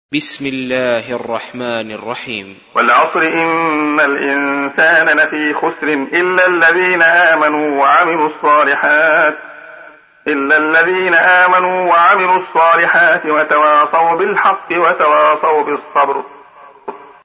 سُورَةُ العَصۡرِ بصوت الشيخ عبدالله الخياط